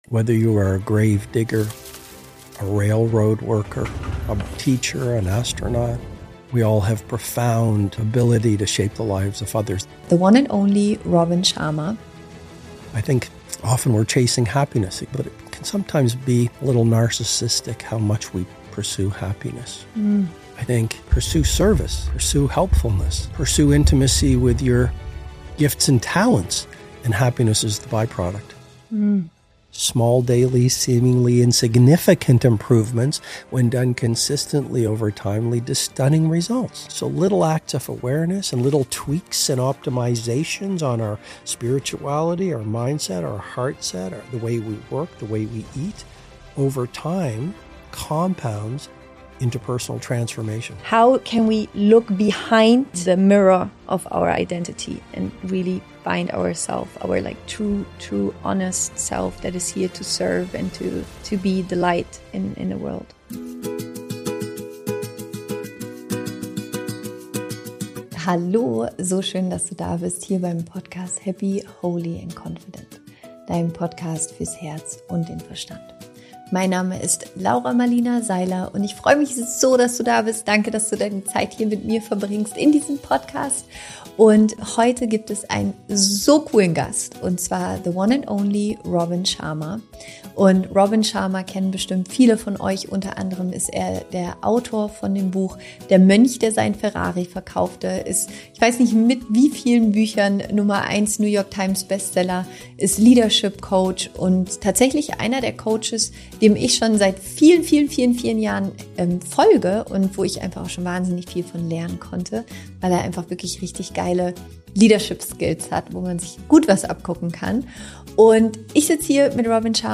Unsere Gesellschaft vermittelt uns, dass man viel Geld braucht, um im Leben zu gewinnen, aber das ist ein Trugschluss. In dieser Folge spreche ich mit Bestseller-Autor und Leadership-Coach Robin Sharma darüber, wie du dich von der Vorstellung befreien kannst, dass Wohlstand nur durch Geld und Status definiert wird und was wahren Reichtum wirklich ausmacht.